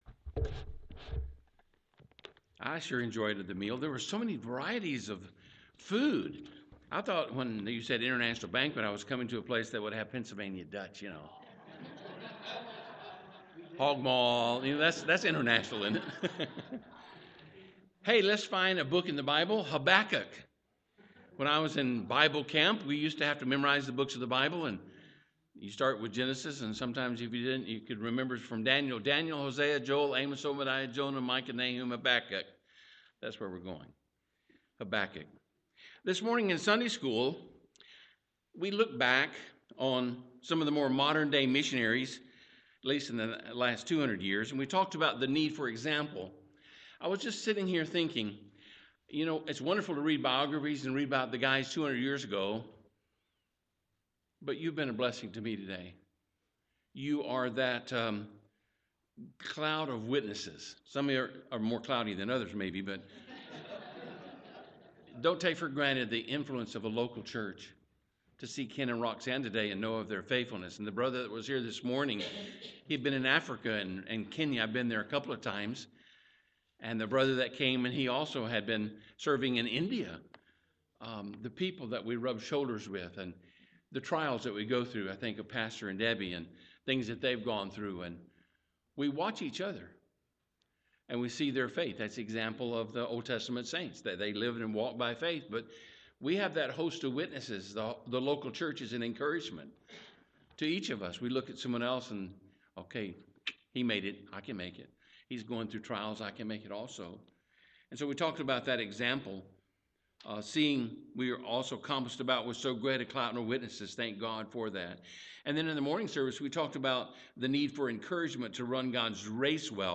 Missions Conference PM
Sermon